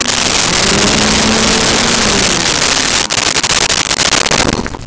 Vacuum Cleaner On Off
Vacuum Cleaner On Off.wav